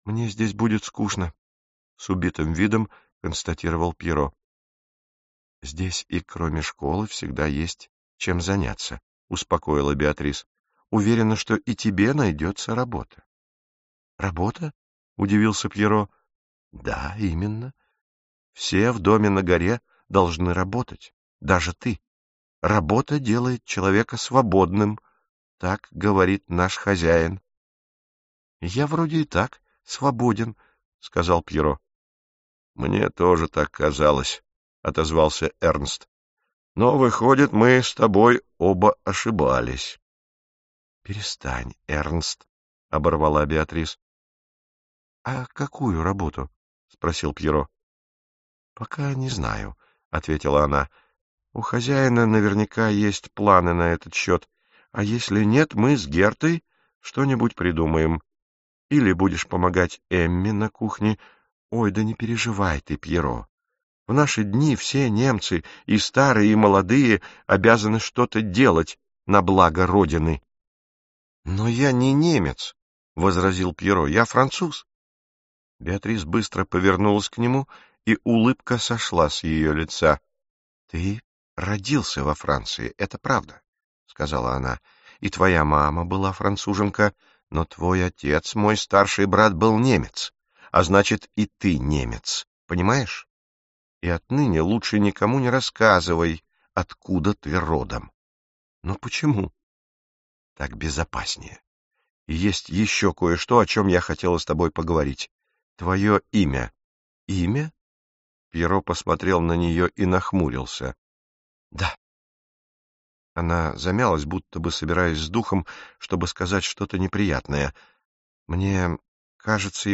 Аудиокнига Мальчик на вершине горы | Библиотека аудиокниг
Прослушать и бесплатно скачать фрагмент аудиокниги